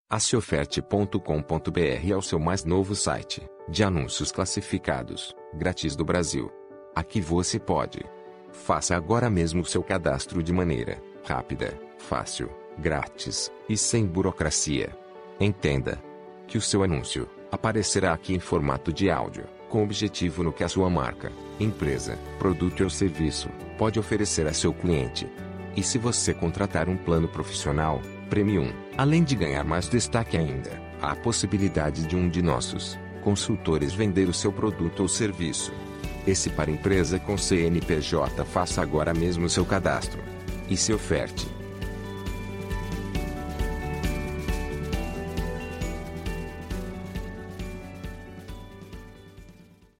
VINHETA-DE-APRESENTACAO-SEOFERTE-1-EDITADA.mp3